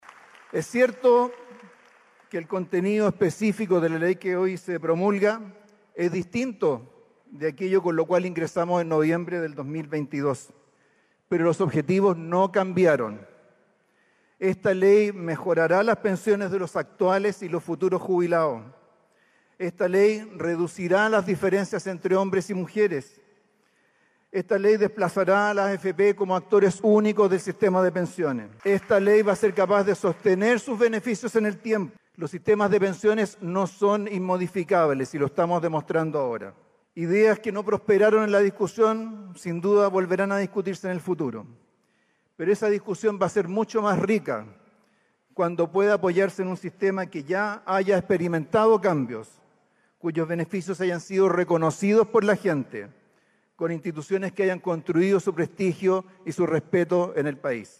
La actividad se realizó el jueves 20 de marzo en el Centro Cultural y Deportivo Chimkowe de Peñalolén.